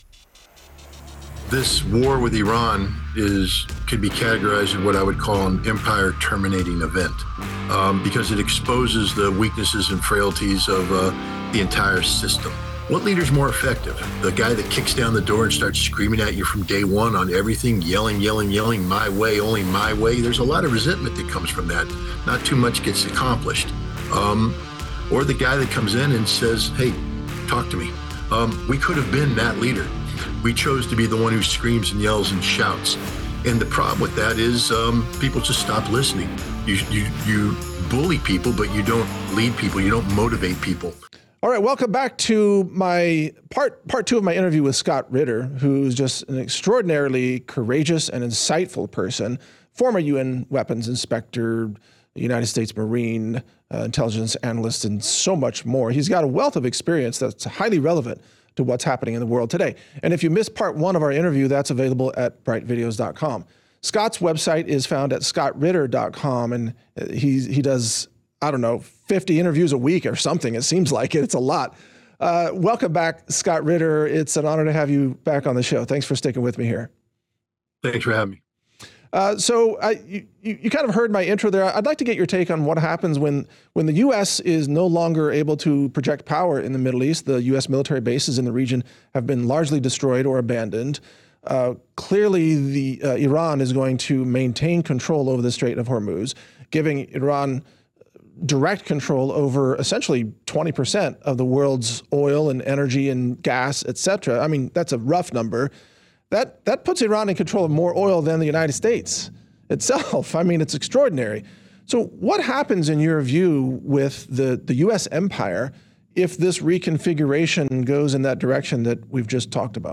Scott Ritter Interview: Empire Collapse, Hormuz Control & The End of U.S. Dominance (PART 2) - Natural News Radio